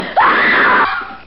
PERSON-Scream+1
Category: Games   Right: Personal
Tags: combat